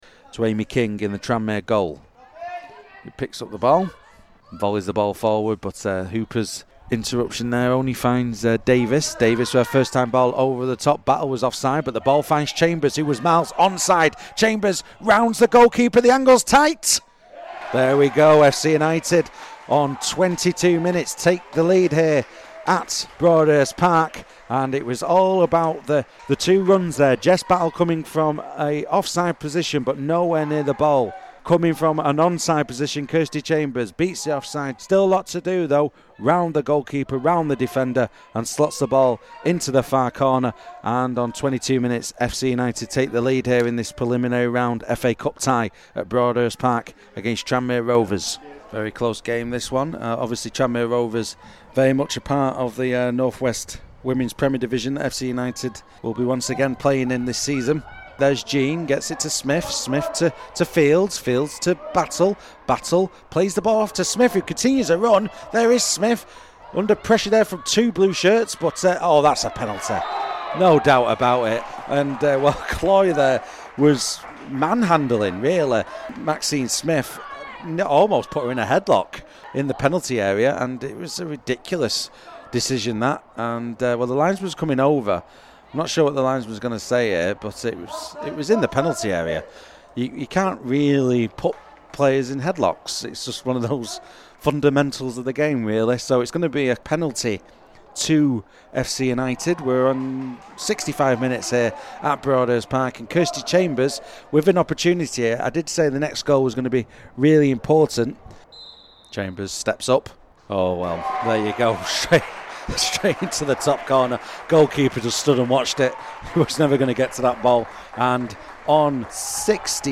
WT Live Goals - Tranmere Rovers (h)